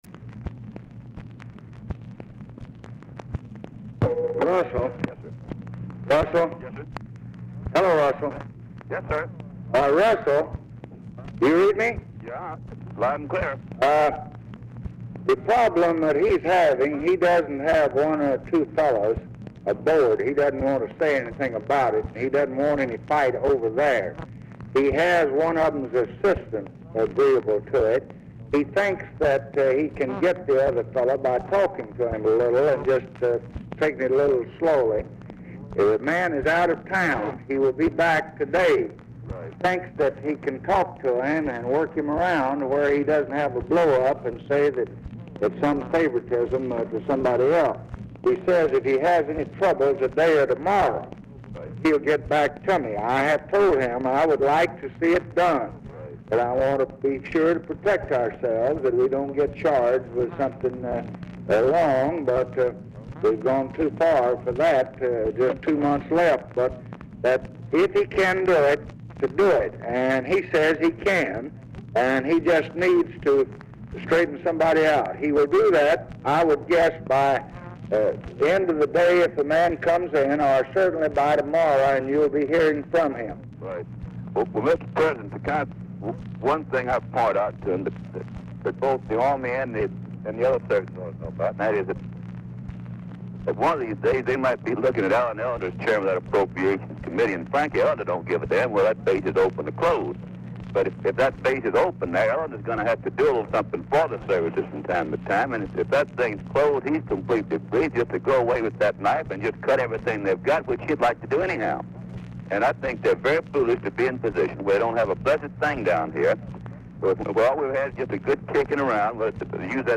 Telephone conversation
Dictation belt
Oval Office or unknown location